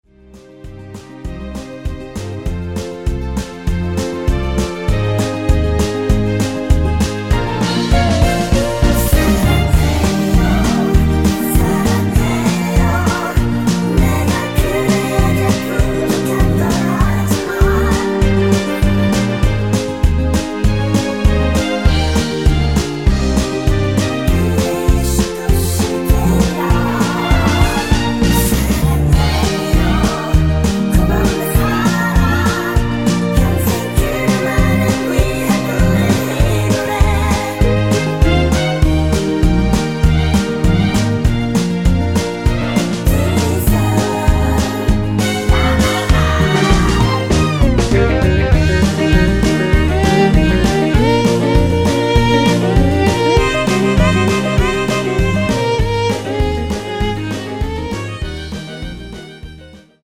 원키에서(+3)올린 코러스 포함된 MR입니다.(미리듣기 확인)
Eb
앞부분30초, 뒷부분30초씩 편집해서 올려 드리고 있습니다.
중간에 음이 끈어지고 다시 나오는 이유는